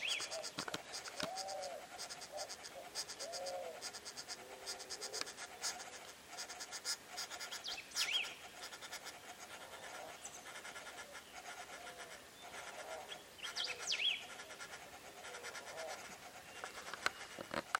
Dryocopus schulzii
Grabación de los sonidos que salían del nido de un casal de Carpintero Negro que hace varias semanas estamos siguiendo con un amigo.
Nome em Inglês: Black-bodied Woodpecker
Fase da vida: Gorducho
Localidade ou área protegida: La Paisanita
Certeza: Gravado Vocal